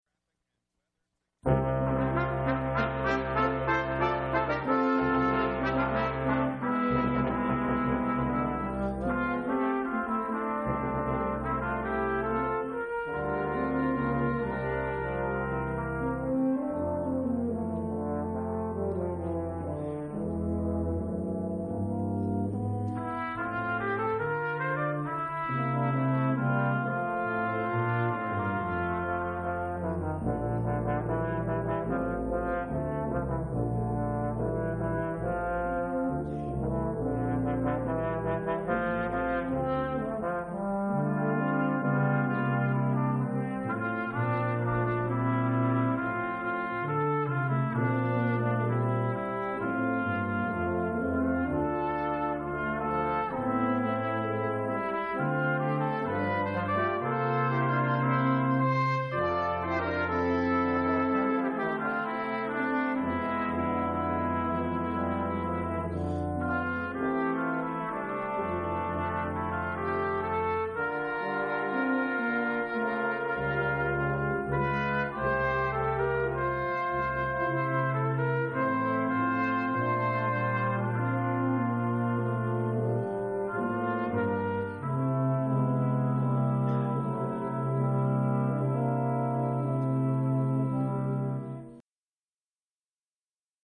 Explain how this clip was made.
Seton Performance Series - 1/18/2004